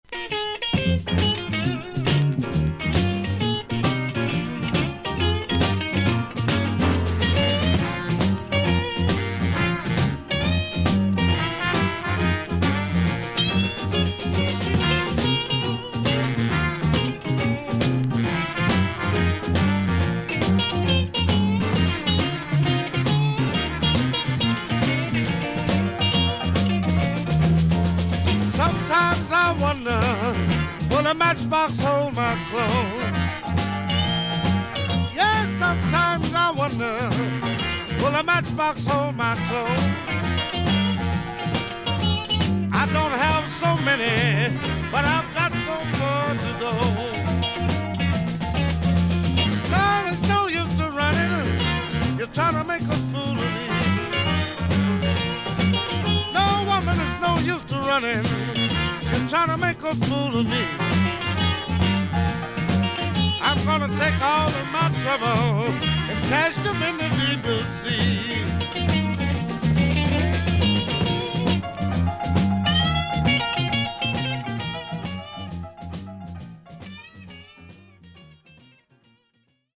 少々サーフィス・ノイズあり。パチノイズは感じませんでした。クリアな音です。
ブルース・ギタリスト/シンガー二人のカップリング・アルバム。